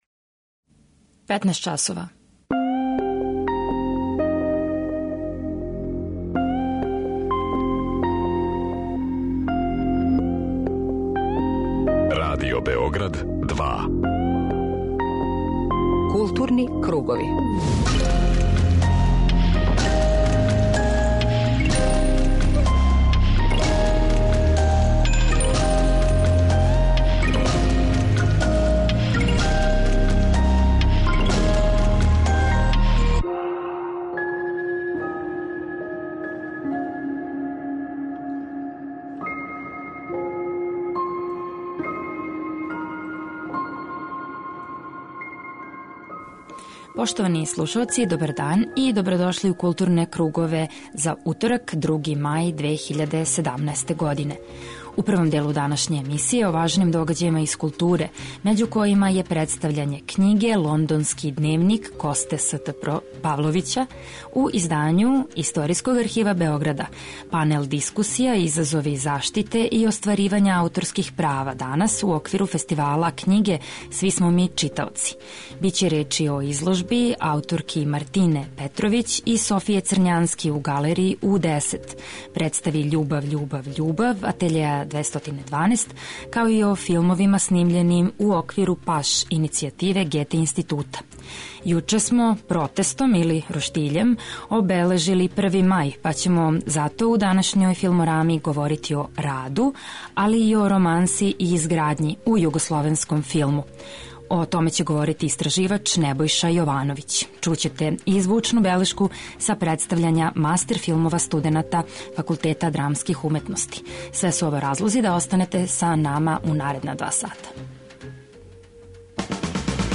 преузми : 41.17 MB Културни кругови Autor: Група аутора Централна културно-уметничка емисија Радио Београда 2.